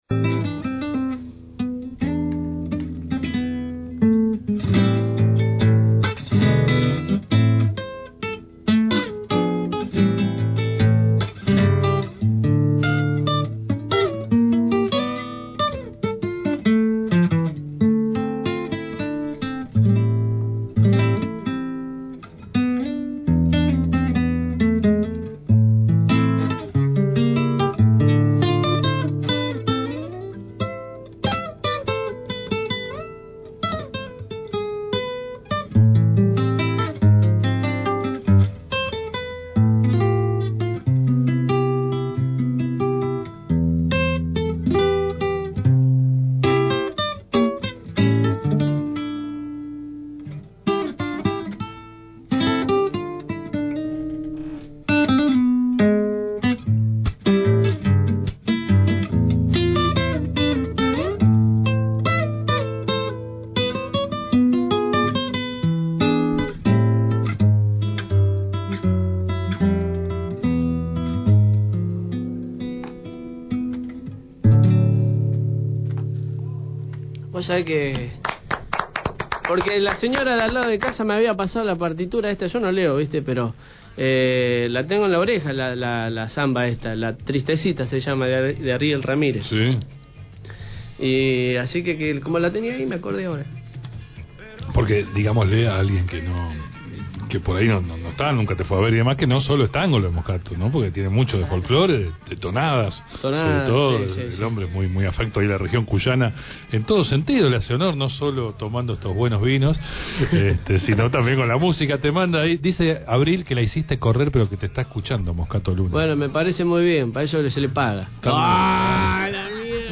también dio lugar a que el artista ejemplificara, viola en mano, cómo trabaja algunos arreglos para el quinteto y, por supuesto, nos regaló algunas canciones que no hicieron más que ratificar que estamos ante uno de los más grandes guitarristas de la actualidad.